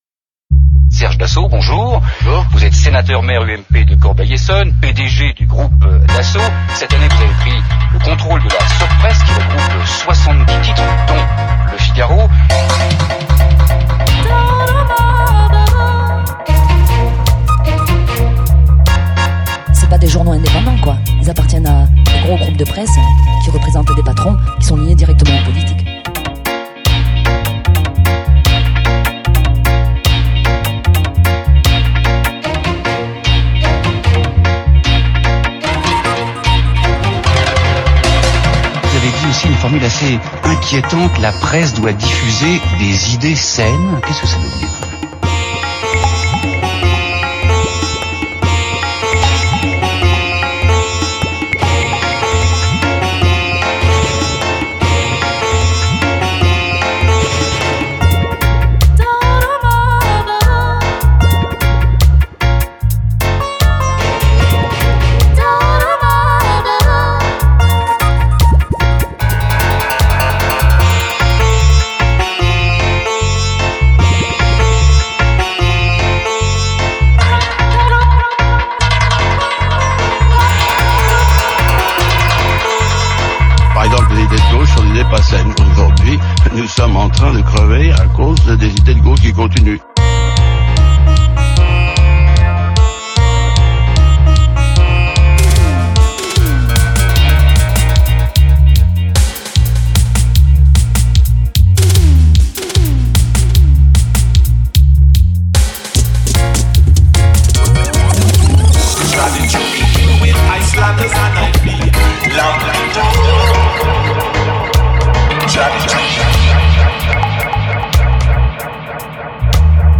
Excellent dub
programmation, saxo et percu
Des idées fortes et une ambiance chaude à souhait